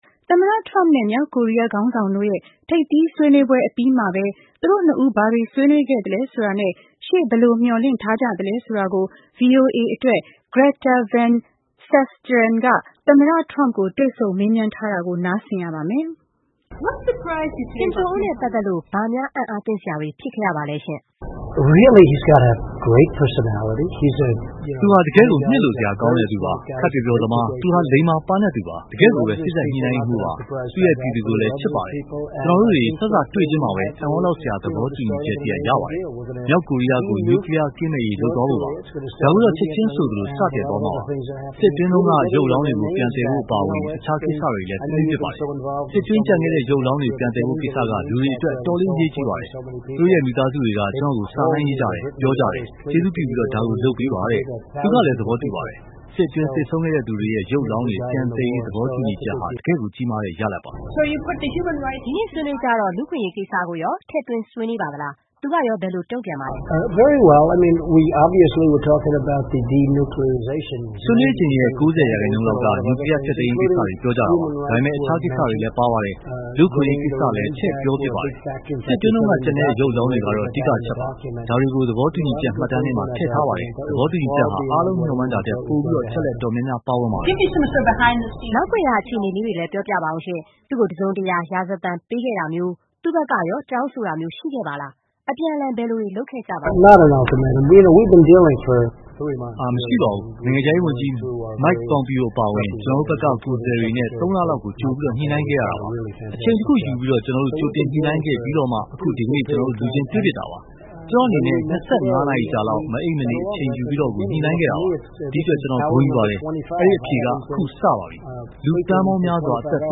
VOA နဲ့ သမ္မတ Trump တွေ့ဆုံမေးမြန်းမှု